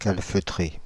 Ääntäminen
IPA: [ˈstɔpə(n)]